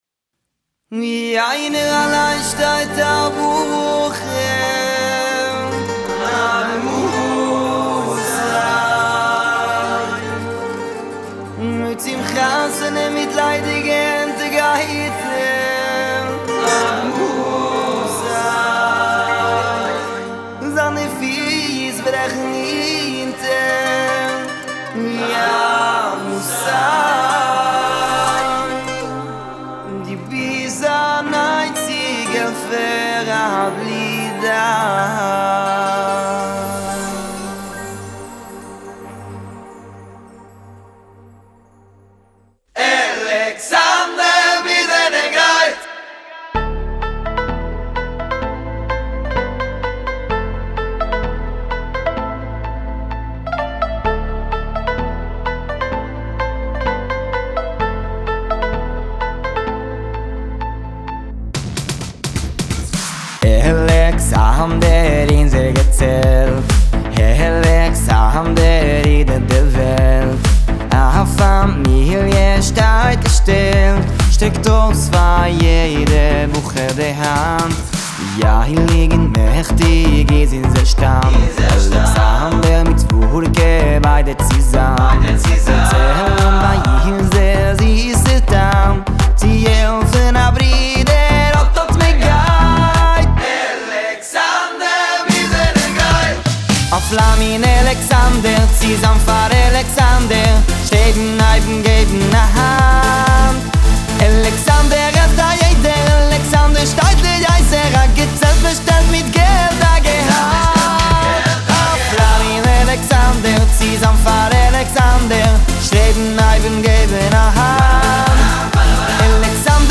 וואו איזה סאונד נקי, ממש מקצועי.